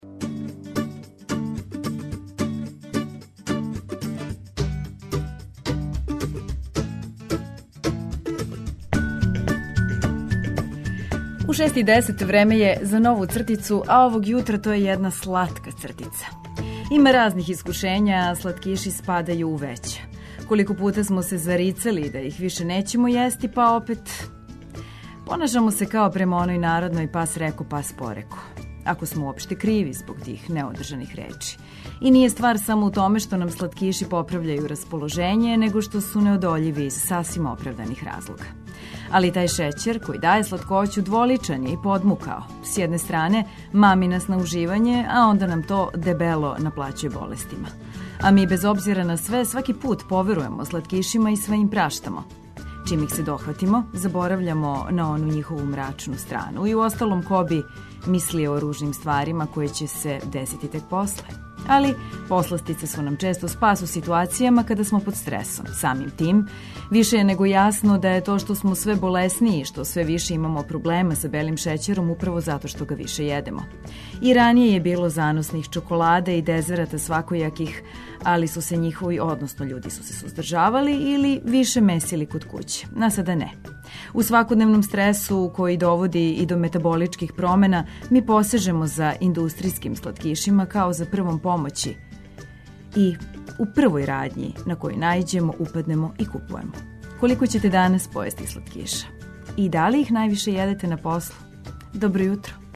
Викенд пред нама најавићемо лепим причама и блиставом музиком.